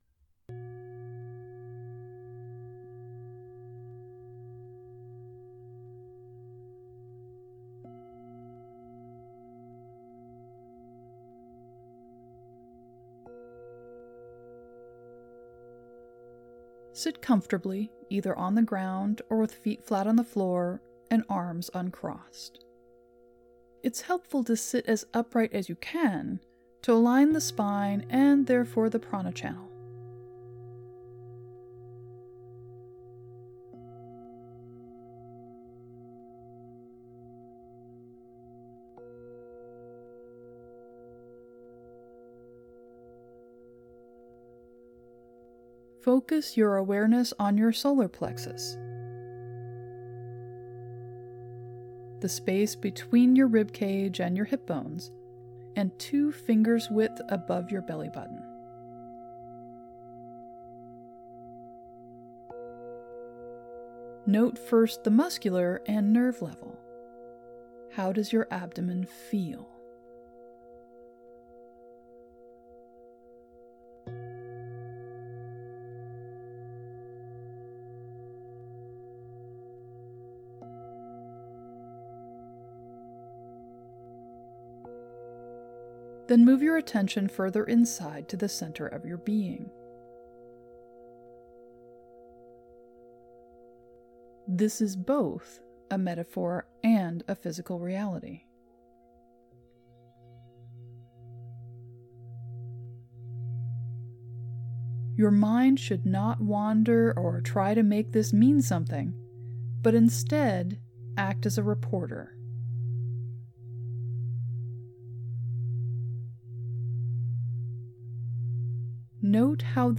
For those who are called to a closer relationship with their higher self or who just want to know more about what the Akashics has to offer, here is meditation for facilitating that connection.
Higher-Self-Connection-Meditation.mp3